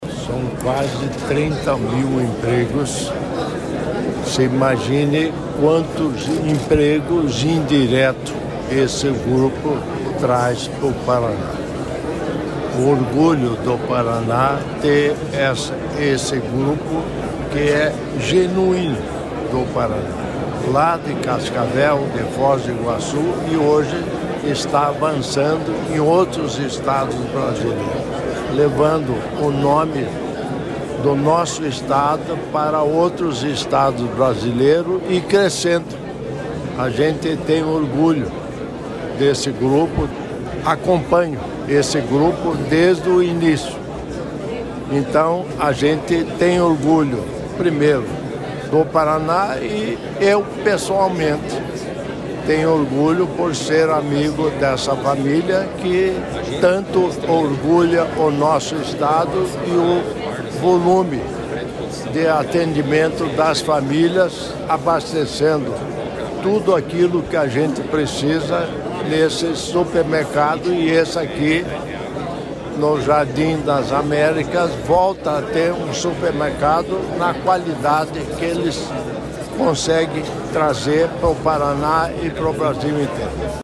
Sonora do governador em exercício Darci Piana sobre mais uma inauguração do Grupo Muffato em Curitiba | Governo do Estado do Paraná